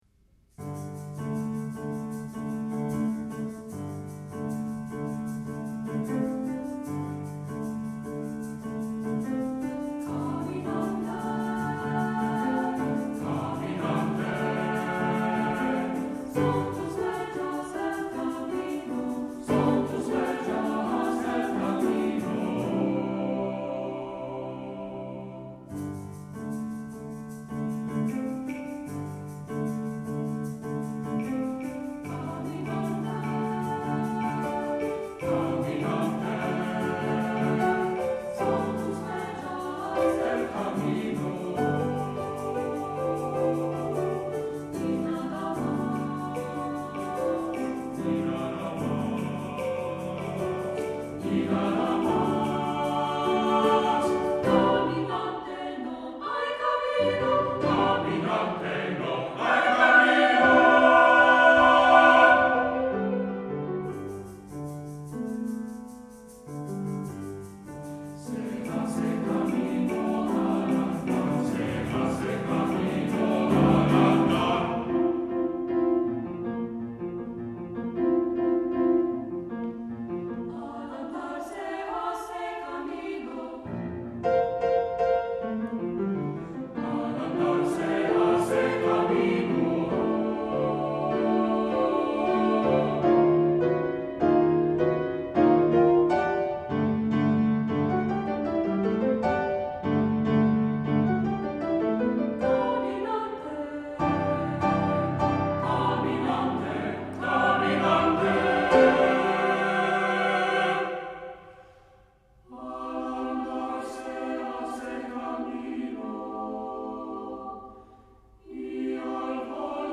Accompaniment:      Piano, Claves, Shaker
Music Category:      Choral